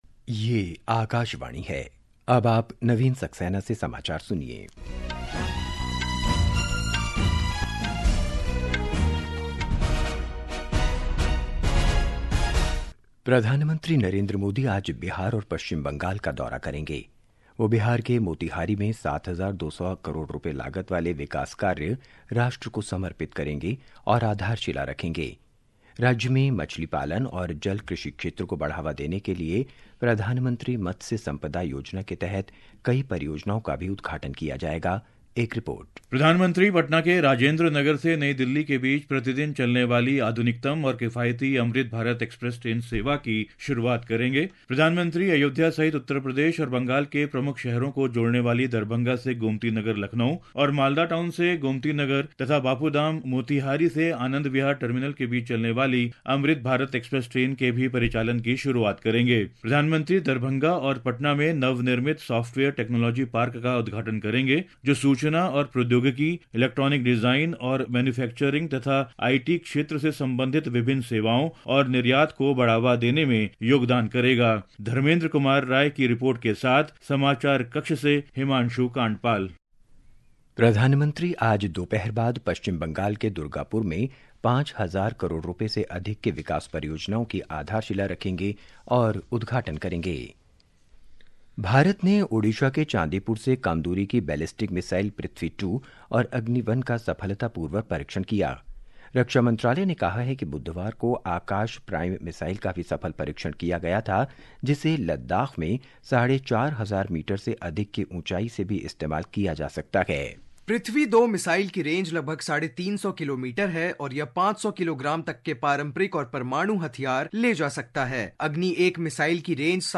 জাতীয় বুলেটিন